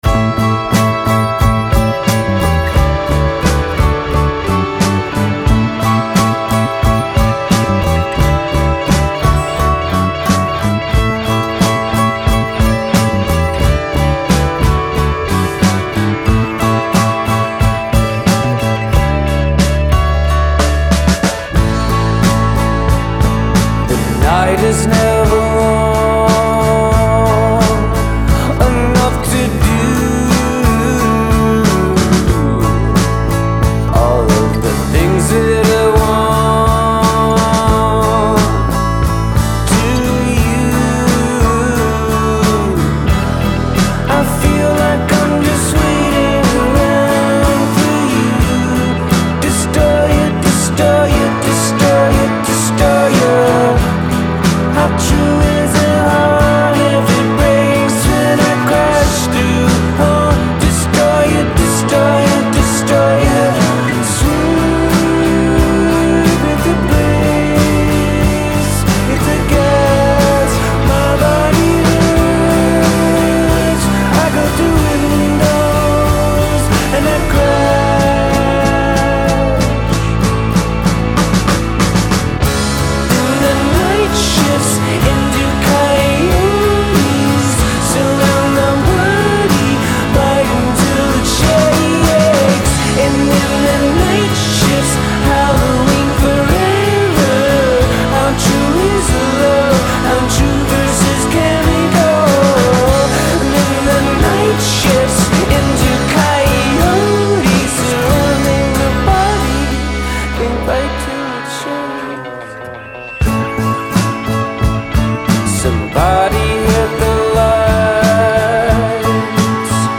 concept rock opera
bass
guitar and vocals
drums
Steel Guitar
piano and synths